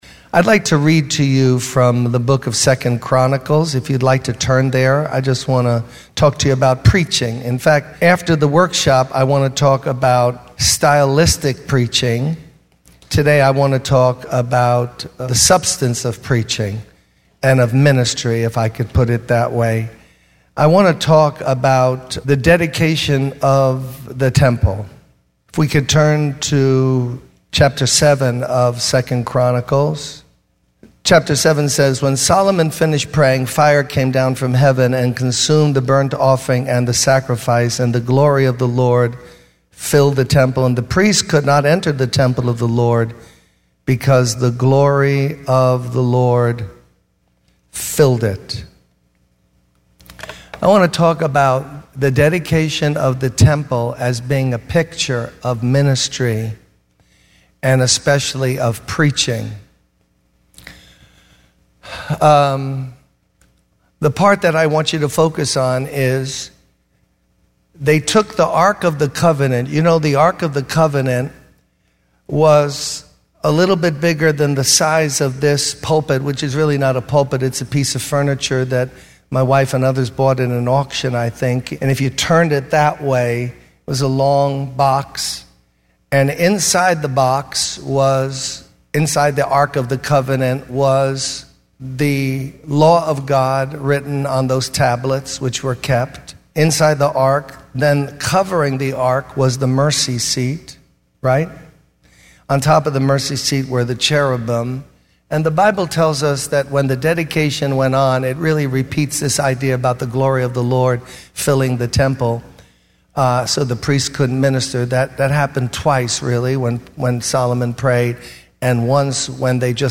In this sermon, the speaker emphasizes the importance of preaching the word of God. He encourages preachers to focus on delivering a message from God's word rather than relying on their own abilities or clever points.